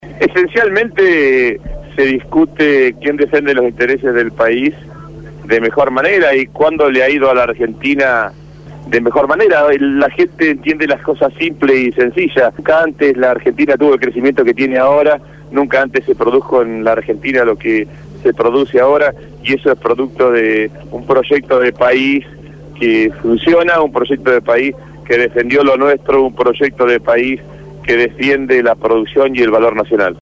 El Ministro de Agricultura de la Nación y candidato a Diputado Nacional por la Provincia de Buenos Aires acompañó a Cristina Fernandez en la Fiesta del Maíz en Chacabuco.